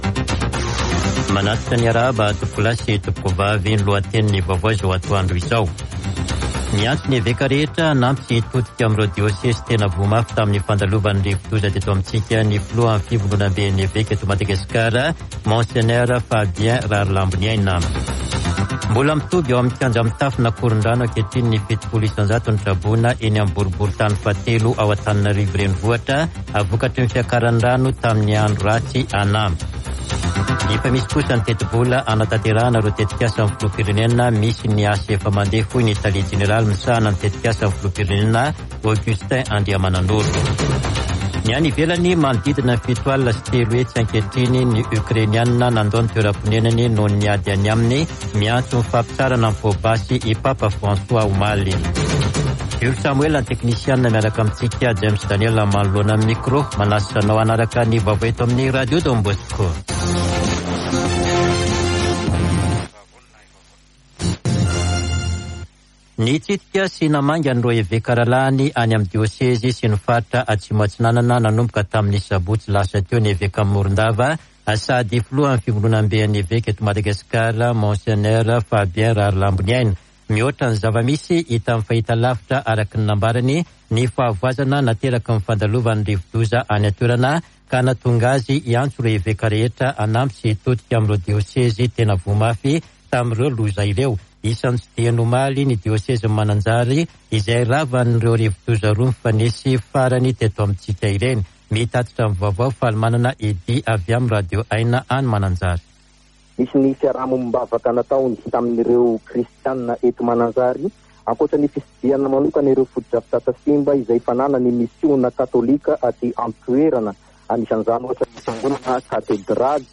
[Vaovao antoandro] Alatsinainy 28 febroary 2022